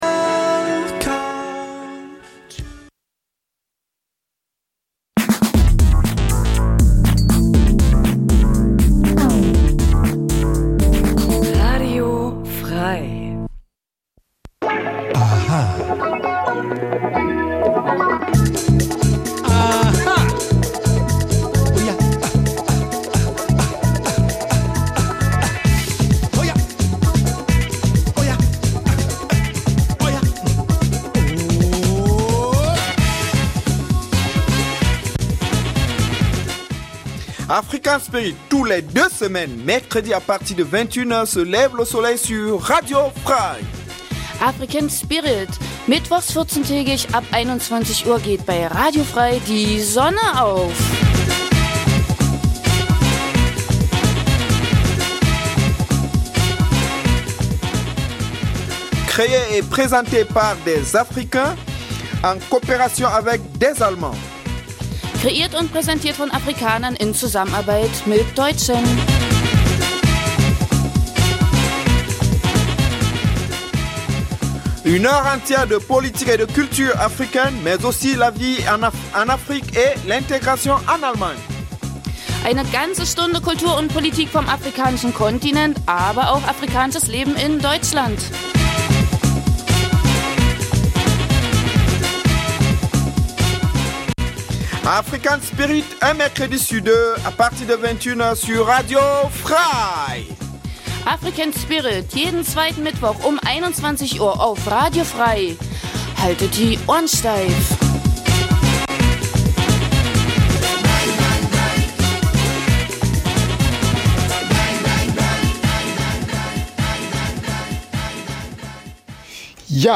Afrikanisches zweisprachiges Magazin Dein Browser kann kein HTML5-Audio.
In einem Nachrichtenblock informiert die Sendung über die aktuelle politische Situation vor allem aus Afrikas Krisengebieten, aber auch über die Entwicklung der Zuwanderungs-politik in Deutschland welche das Leben vieler Afrikaner bei uns beeinflußt. Neben aktuellen Nachrichten gibt es regelmäßig Studiogäste, Menschen die in irgendeiner Form etwas mit Afrika zu tun haben: Zum Beispiel in Thüringen lebende Afrikaner, die uns über ihr Heimatland berichtet aber auch davon welche Beziehungen sie zu Deutschland haben oder Deutsche die aus beruflichen Gründen in Afrika waren und uns über ihre Erfahrungen berichten. Die Gespräche werden mit afrikanischer Musik begleitet.